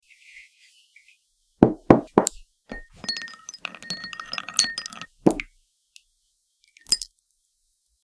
Stirring up some spooky chic ASMR. sound effects free download